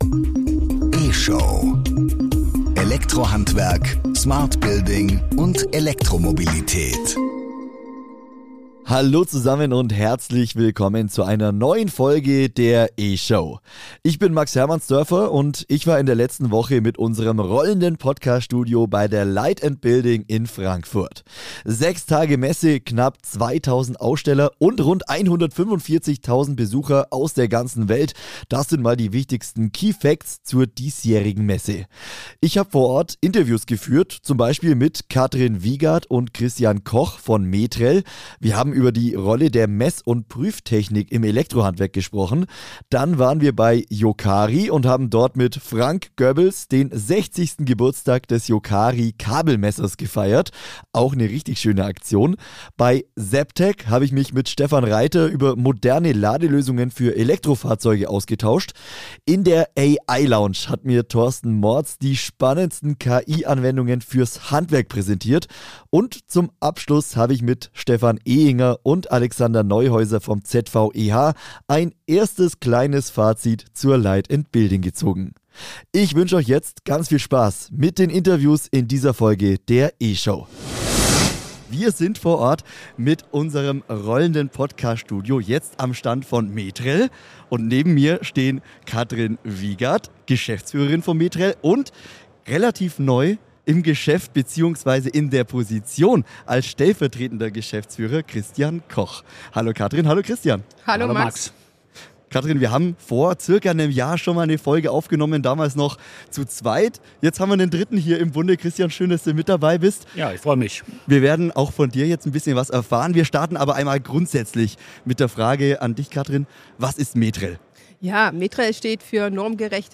Mit dem rollenden Podcaststudio war das Handwerker Radio Team Mitte März bei der Light+Building in Frankfurt unterwegs.